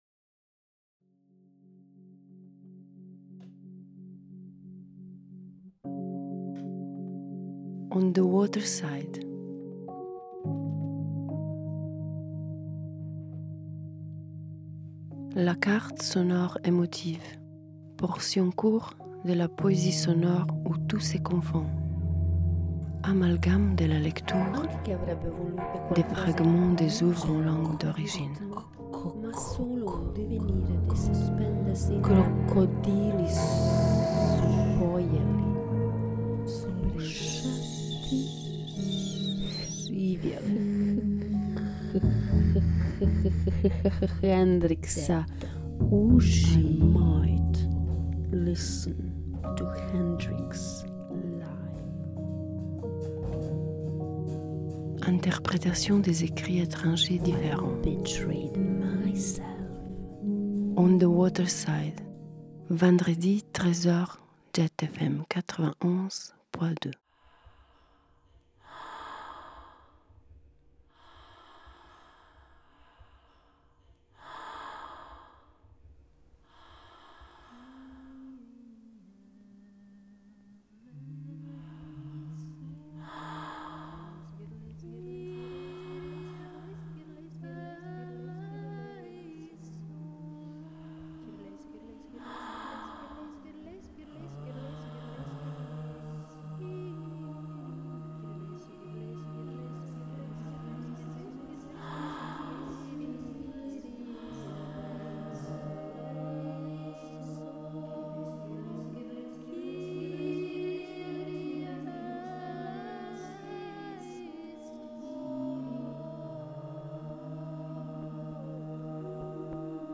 enorme merci pour ton berimbau. Les voix (grec ancien), orgue, les bruits,ambiances, mixage, text